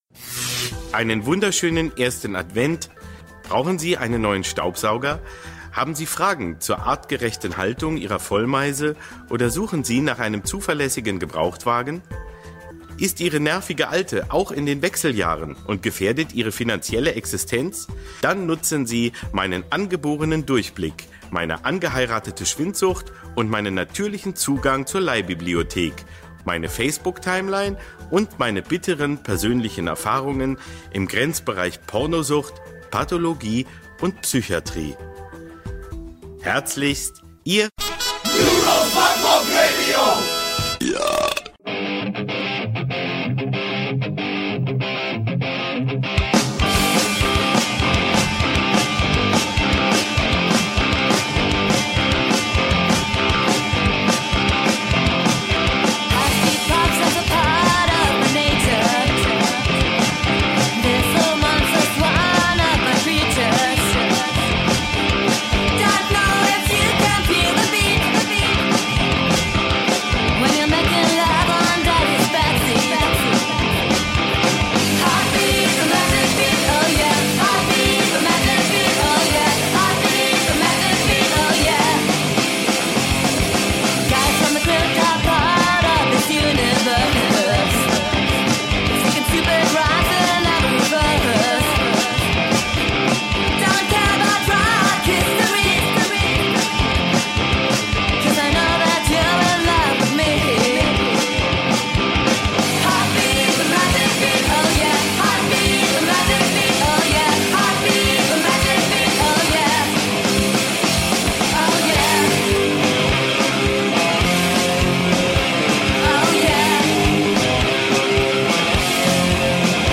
Was haben wir denn heute? 5 Hörerwünsche, 2 Geburtstagsgratulationen, 3 Cover-Songs, 2 Konzerthinweise und überhaupt: Beiträge aus aller Welt…